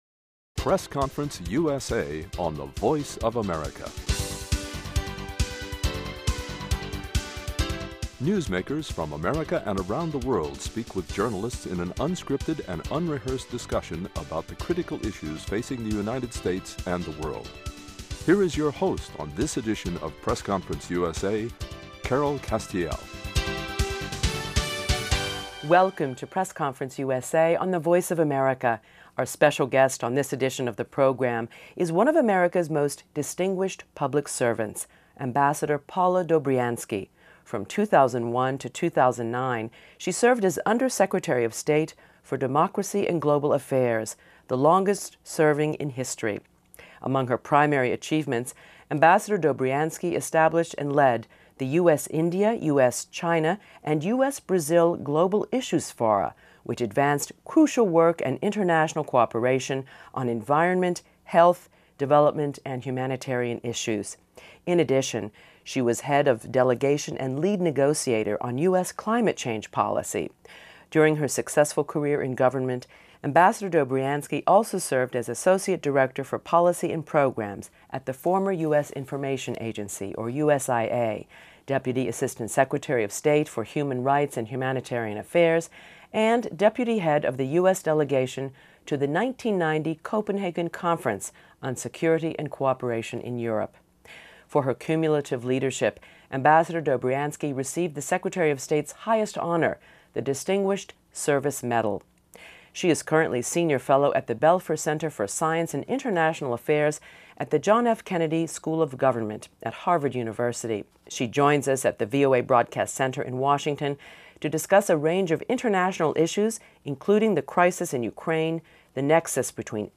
AMBASSADOR PAULA DOBRIANSKY On this edition of the program, a conversation with Ambassador Paula Dobriansky, Senior Fellow at the Belfer Center for Science and International Affairs at Harvard University and former Under Secretary of State for Democracy and Global Affairs.